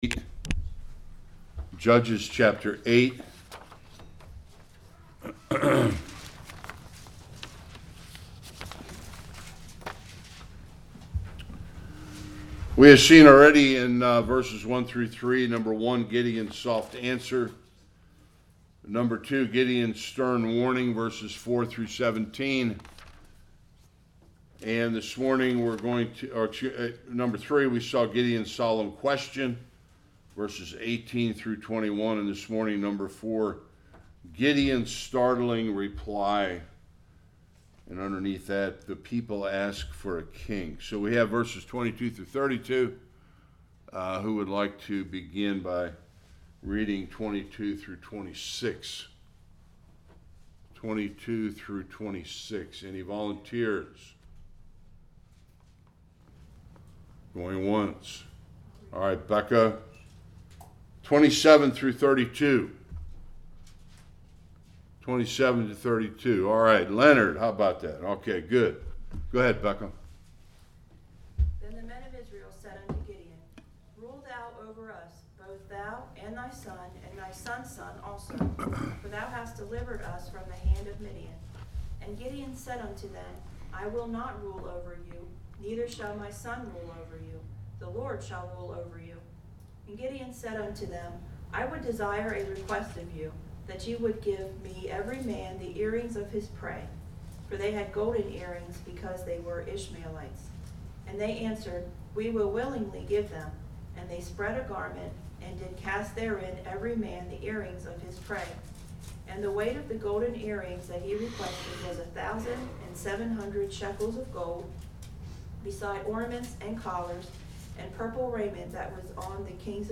22-35 Service Type: Sunday School Gideon’s pride led him to a serios error at the end of his life.